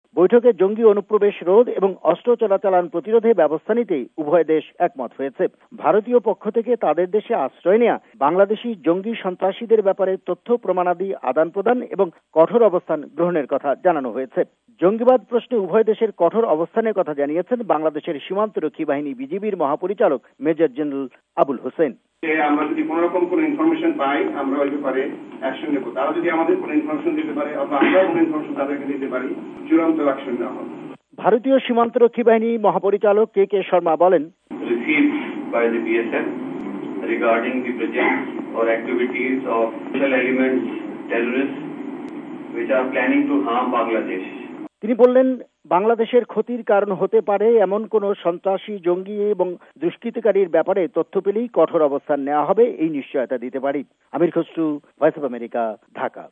রিপোর্ট (বৈঠক)